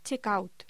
Locución: Check out